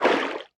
File:Sfx creature babypenguin swim slow 04.ogg - Subnautica Wiki
Sfx_creature_babypenguin_swim_slow_04.ogg